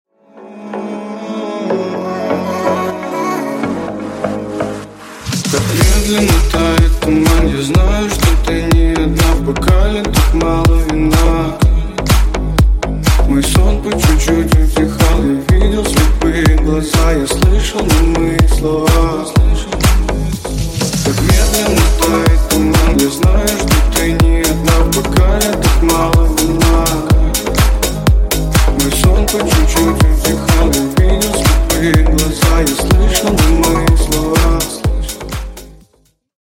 Грустные Рингтоны
Рингтоны Ремиксы » # Танцевальные Рингтоны
Скачать припев песни